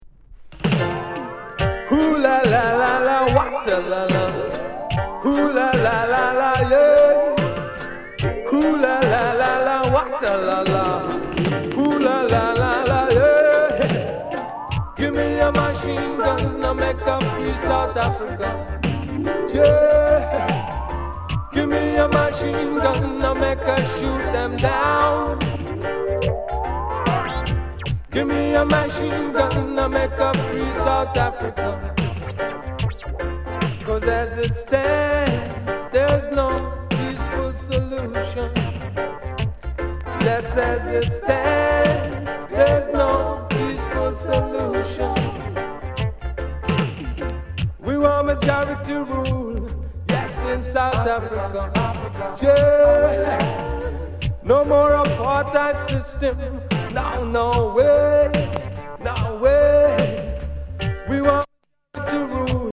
VG�� ������ WICKED DIGI ROOTS